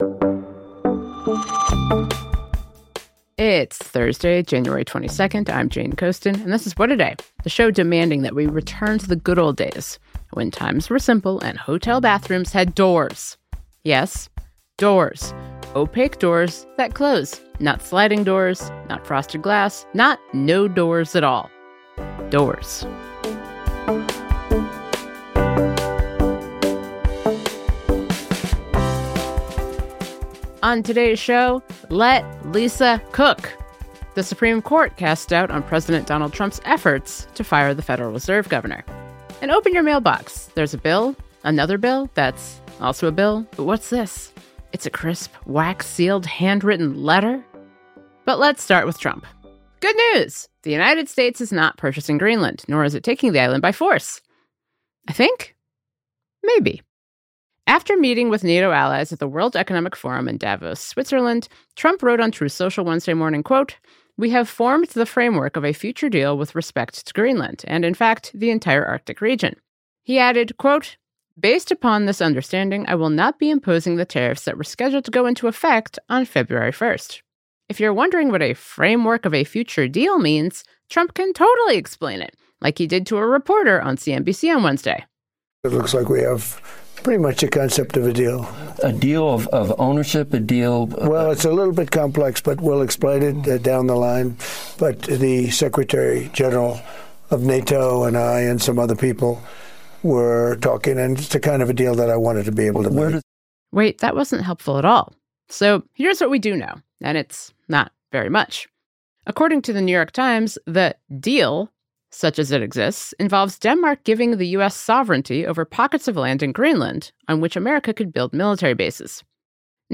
To talk more about Trump’s numbers, we spoke to Dan Pfeiffer.